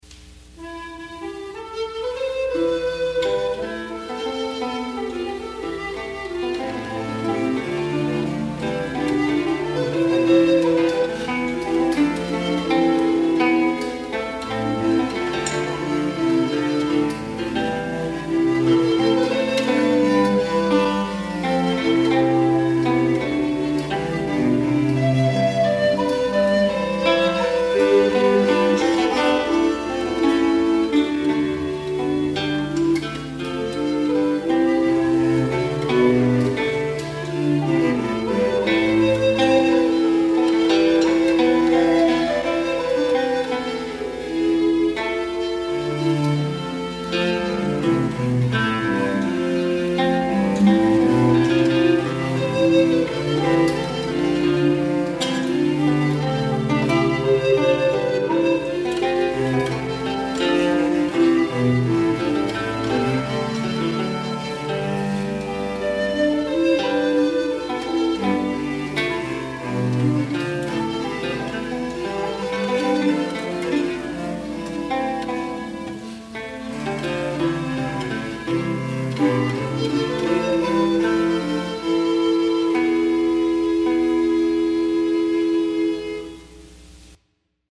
| Instrumental Ensemble 'Court of Cracow' session, 1973
Successive points of imitation characterize this Fantasia as is the case with much instrumental and vocal work of the 16th century.